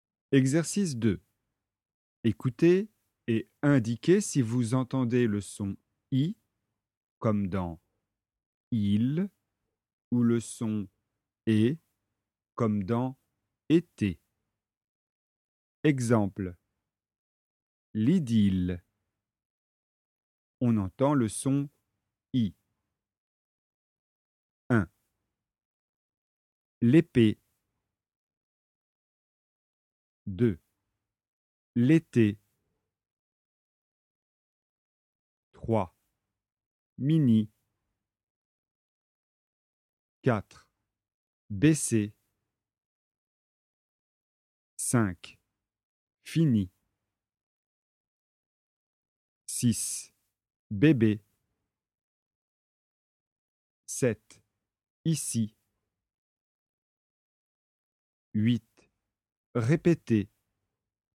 🔷  Exercice 2 : écoutez et indiquez si vous entendez le son « i » comme dans "île" ou le son « e » comme dans "été".